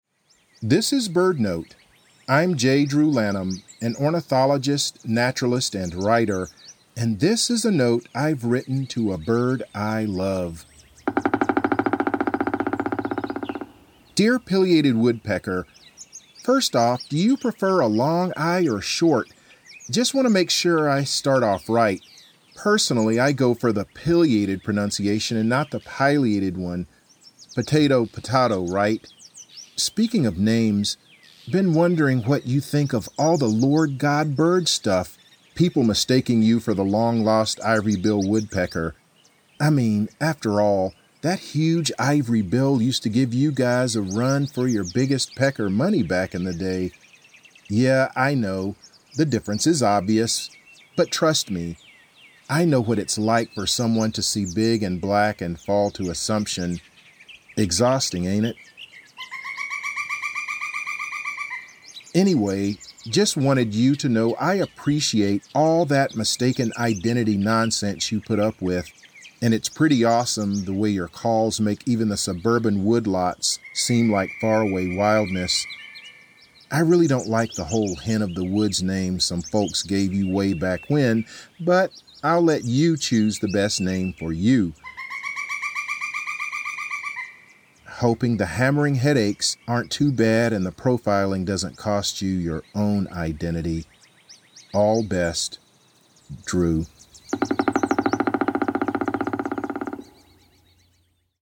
In this episode, ornithologist J. Drew Lanham reads a letter he has written to a Pileated Woodpecker, a large species of woodpecker that is sometimes mistaken for the Ivory-billed Woodpecker.